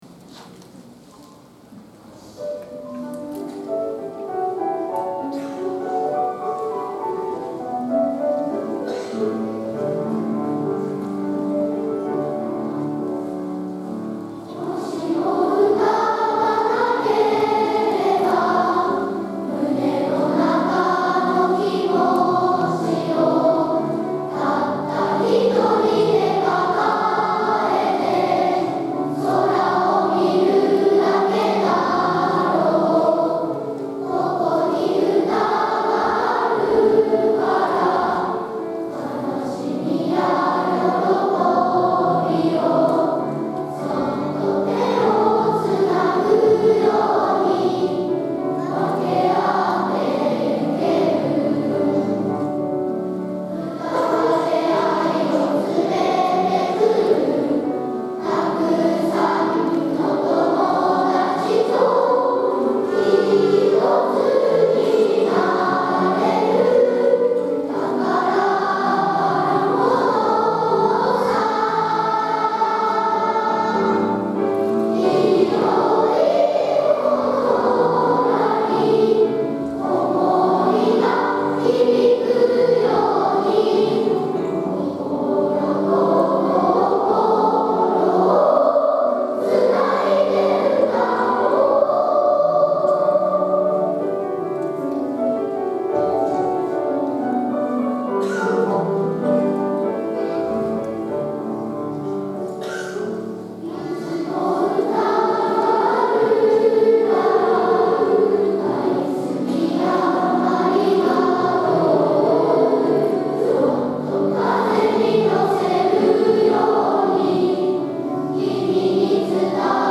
大空ありがとうコンサート
つないで歌おう」会場2部合唱です。